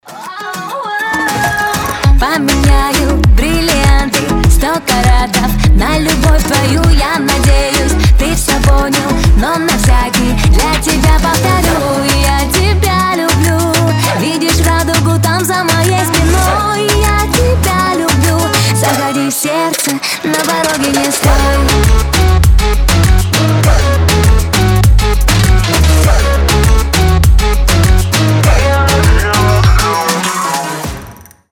поп
зажигательные
заводные
Moombahton
кастаньеты